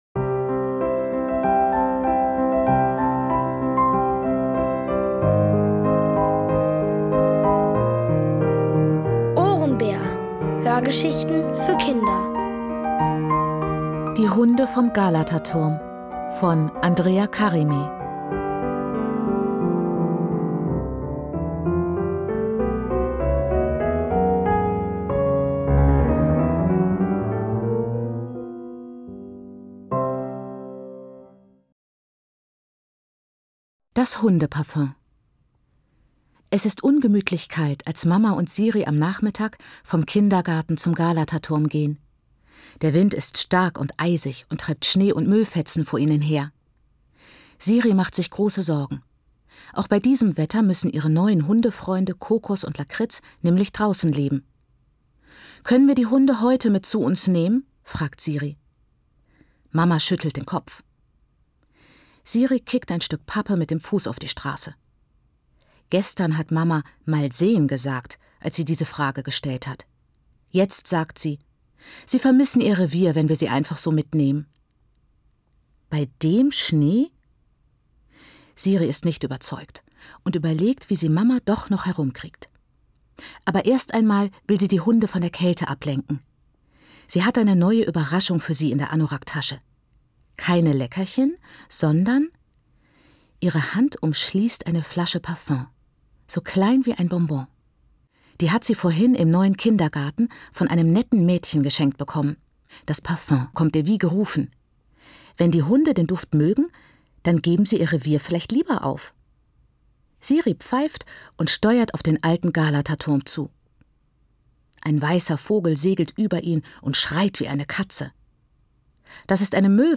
Von Autoren extra für die Reihe geschrieben und von bekannten Schauspielern gelesen.
OHRENBÄR-Hörgeschichte: Die Hunde vom Galataturm (Folge 2 von 6)